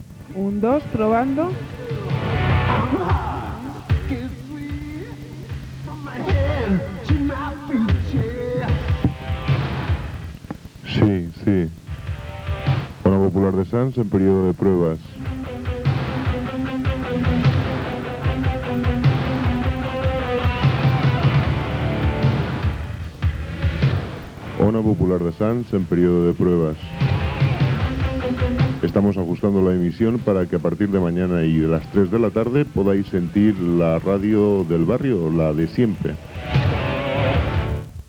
Emissió en proves amb identificació.
FM